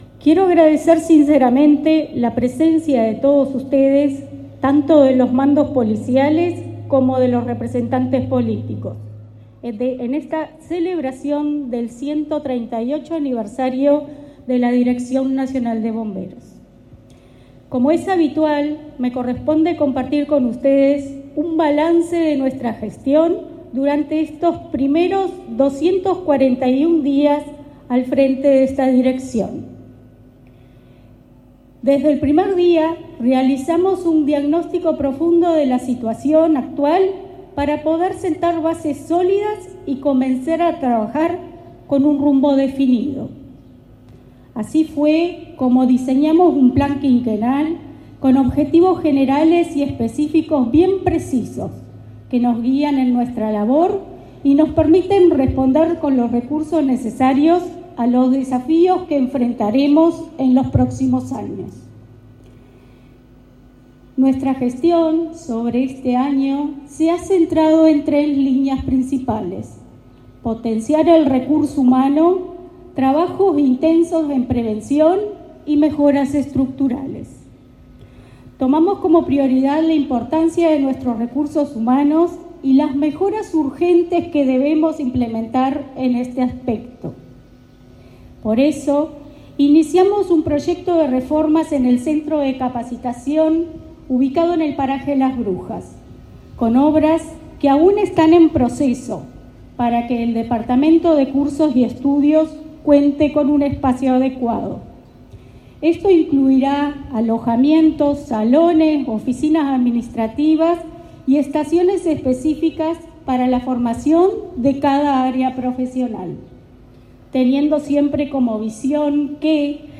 Palabras de la directora nacional de Bomberos, Valeria Vasconcellos
La directora Nacional de Bomberos, Valeria Vasconcellos, se expresó durante el 138.° aniversario del servicio.